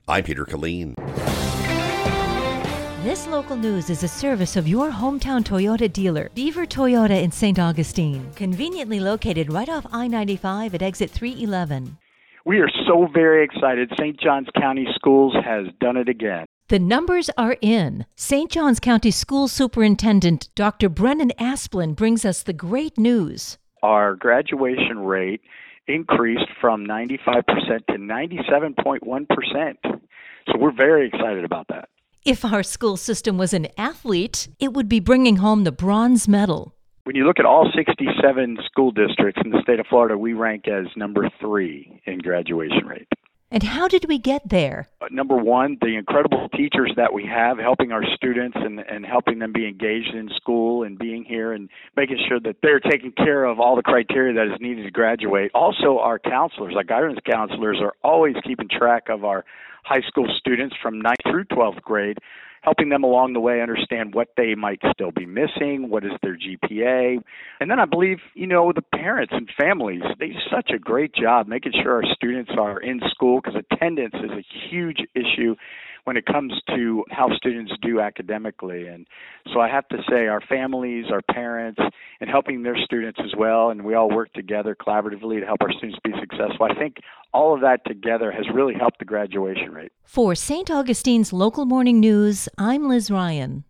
On January 12, 2026, Superintendent Dr. Asplen spoke with Beach 105.5 News Radio about a recent report from the Florida Department of Education that ranks St. Johns County School District 3rd in the state for graduation rates.